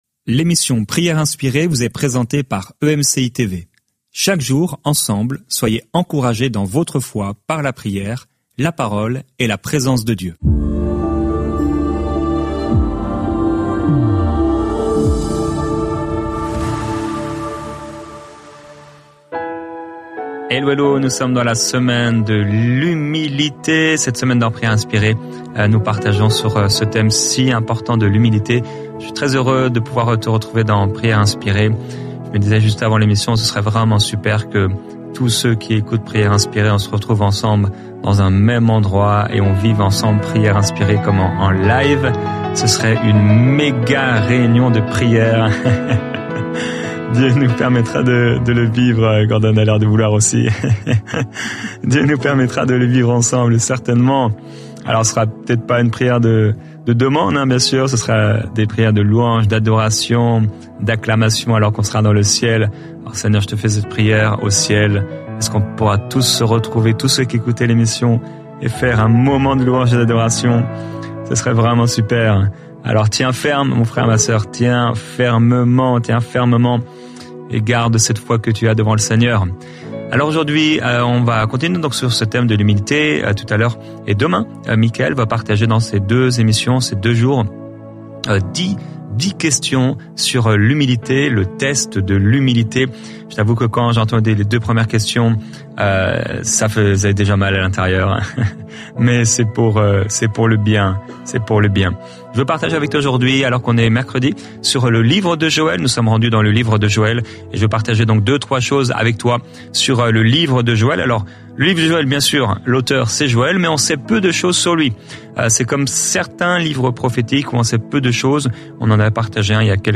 Au programme, une pensée du jour, un temps de louange, l'encouragement du jour et un temps de prière et de déclaration prop